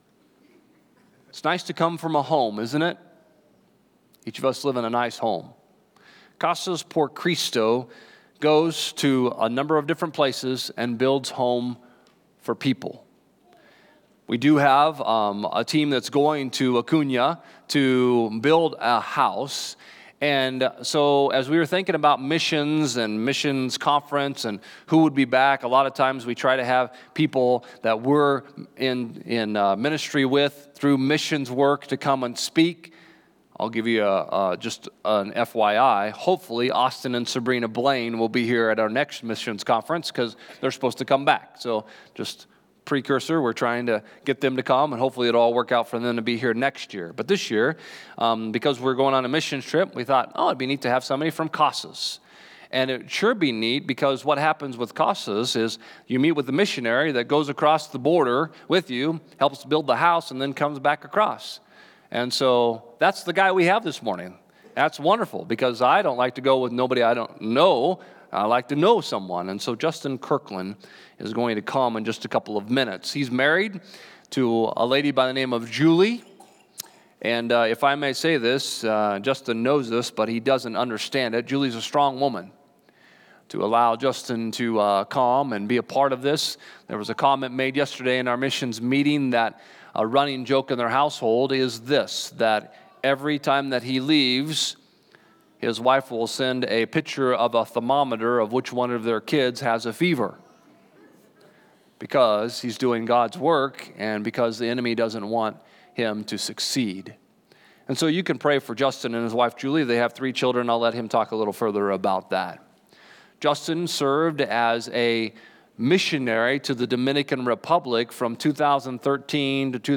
Global Outreach Conference - First Wesleyan Church